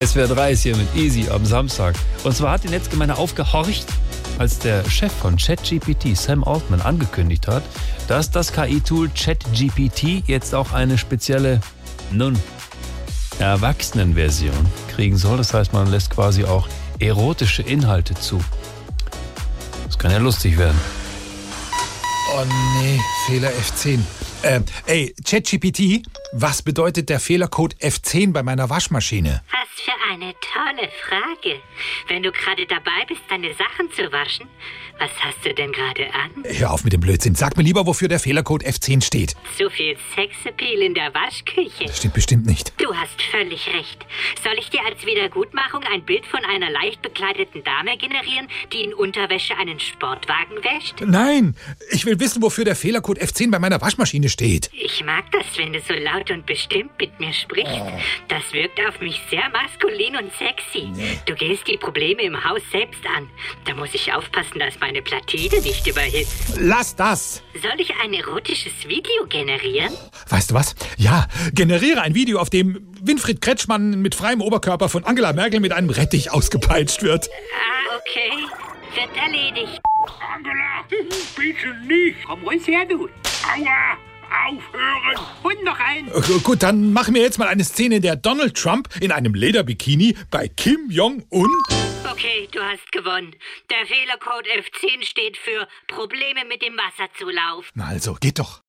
Saubere Komödien Unterhaltung Witz Lachen Comix Comedy Brüller Andreas Müller Gag Comic Downloaden Komödie Lustiges Stand-up Comedy SWR3 SWR ARD Cartoons